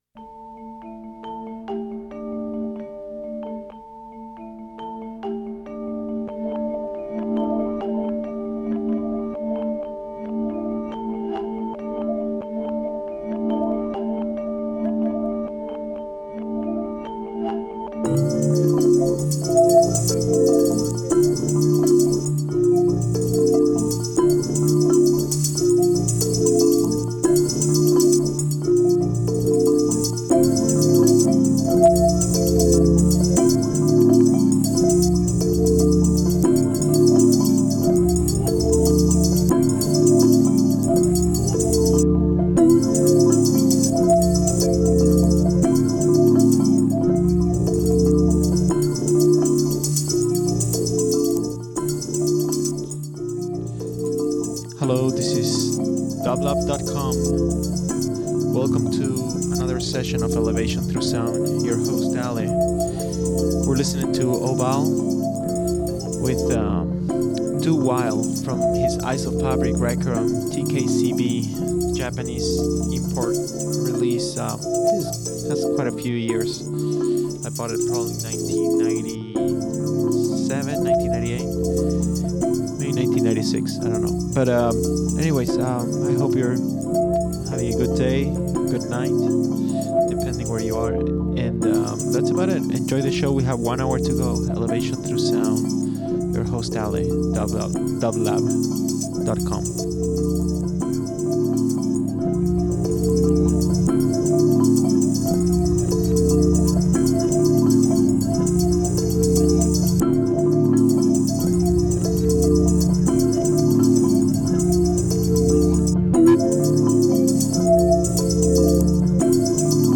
Ambient Avant-Garde Dream Pop Rock